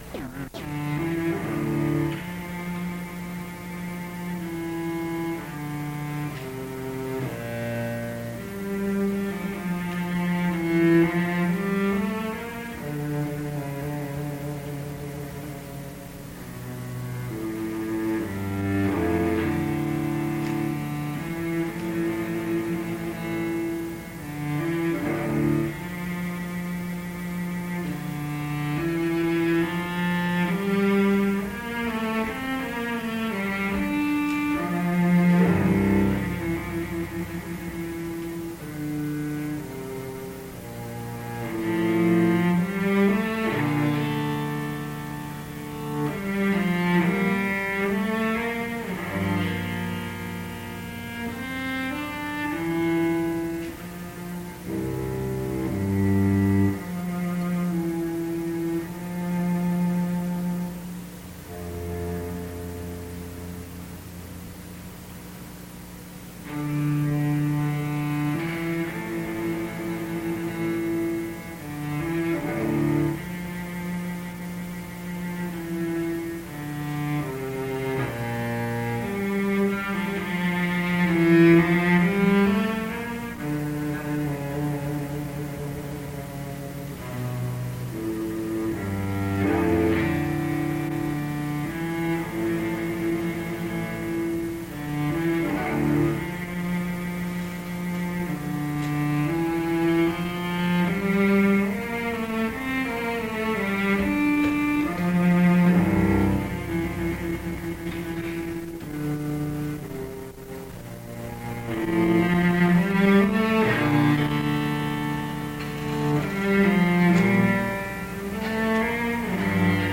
Digitized 7" open reel-to-reel tape recording of Mies van der Rohe's memorial service held in S. R. Crown Hall on Oct. 25, 1969 .
The recording includes solo cello performances by Janos Starker and remarks by James Johnson Sweeney.